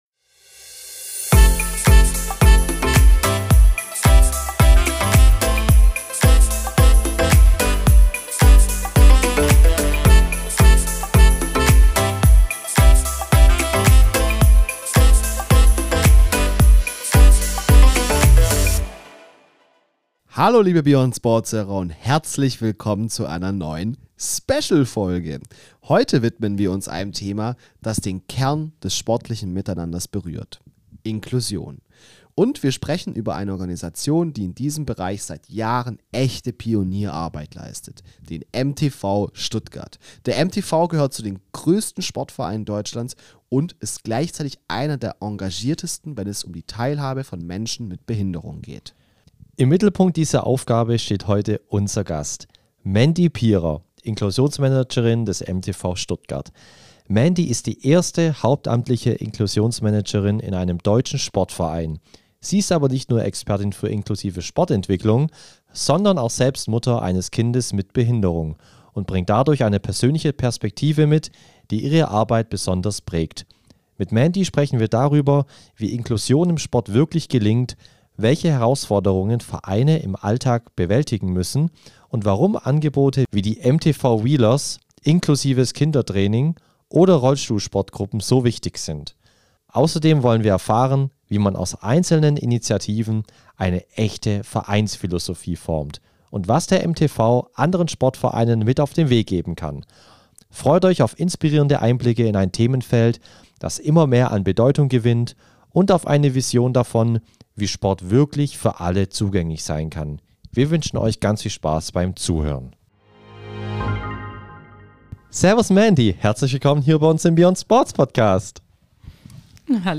Wir setzen uns daher in jeder Folge mit Athleten zusammen, sprechen über seine Karriere und die Faszination der Sportart.